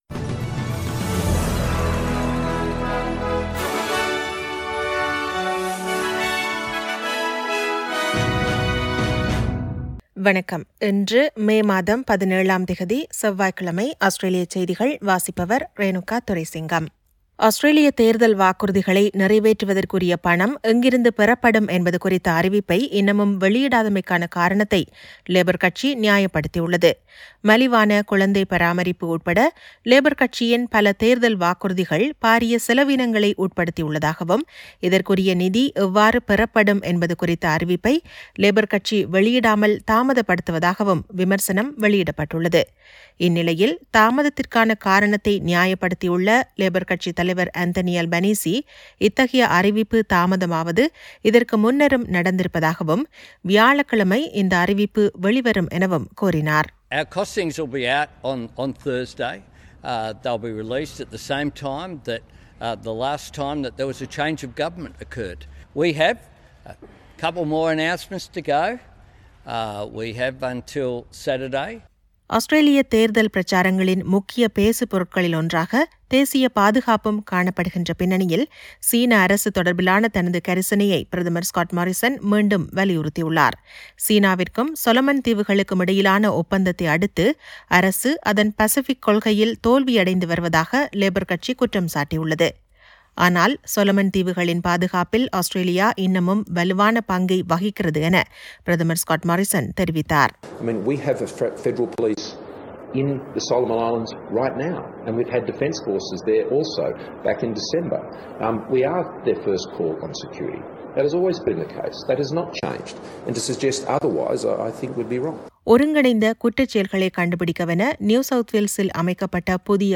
Australian news bulletin for Tuesday 17 May 2022.